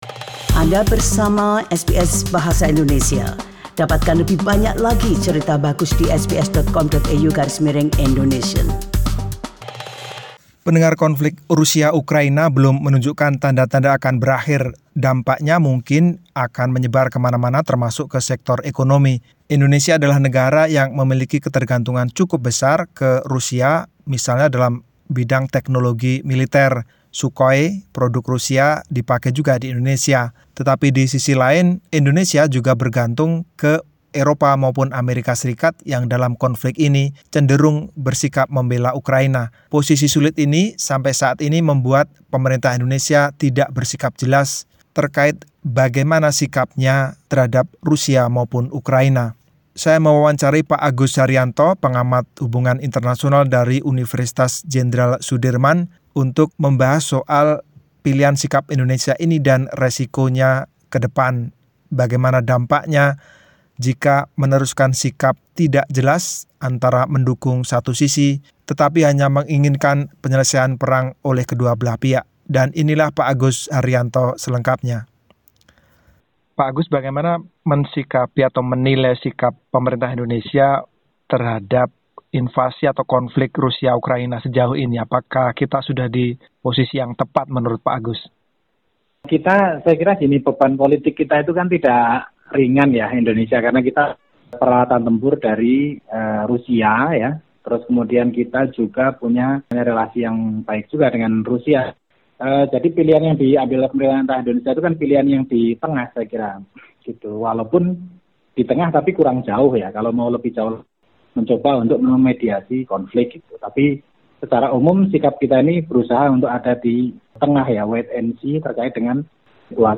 pilihan sikap Indonesia dan resiko-resikonya dalam wawancara berikut ini.